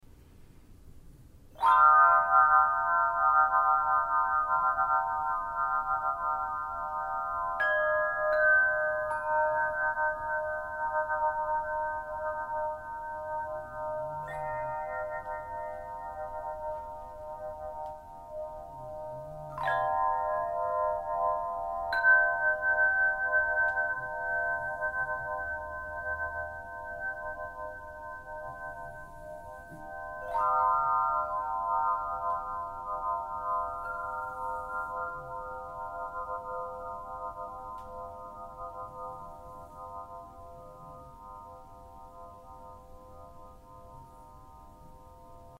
Swinging 9bar Chime.
Creates an experience of magical spherical sound in its layered consistency and its wholesome harmonizing effect.